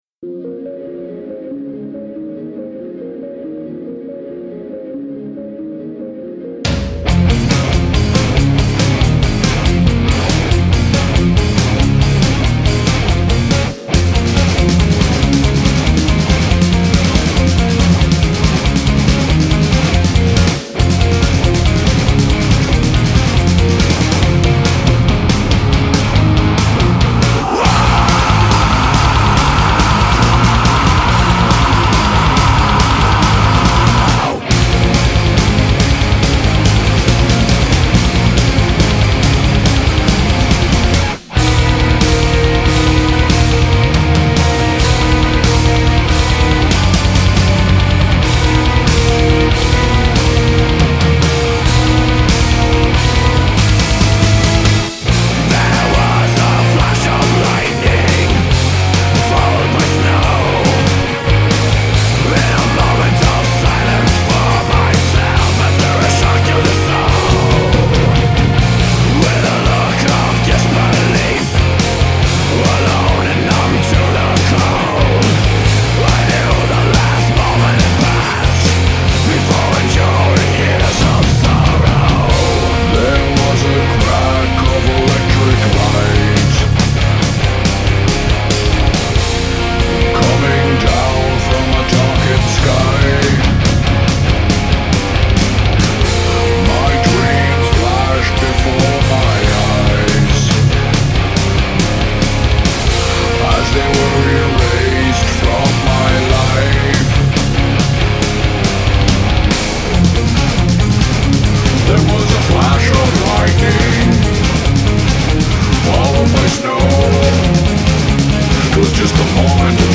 black and doom metal band